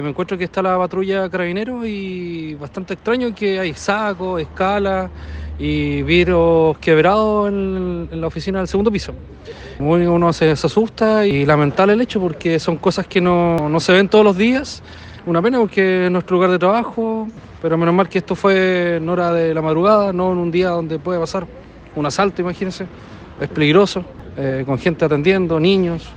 Uno de los trabajadores relató a La Radio que cuando llegó a iniciar su jornada se encontró con personal policial, que ya estaban adoptando el procedimiento de rigor.